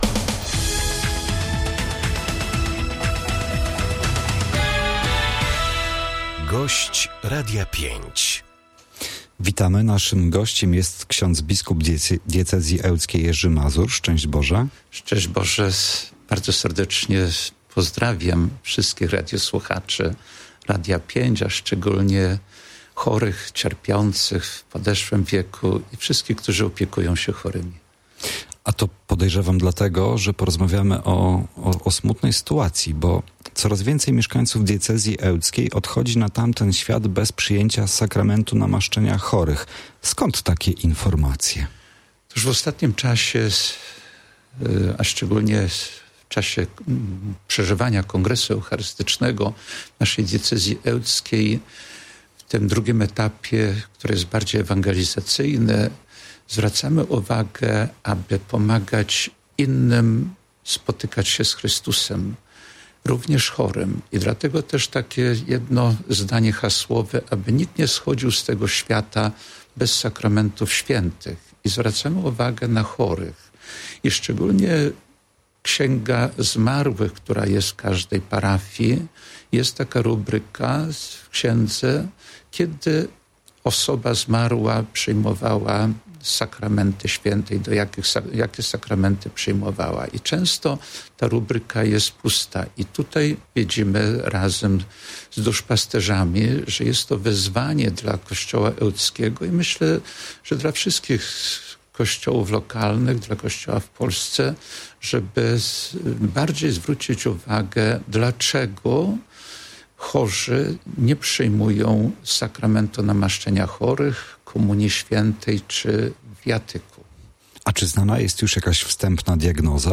06-05.-Gosc-biskup-Mazur-z-jinglami.mp3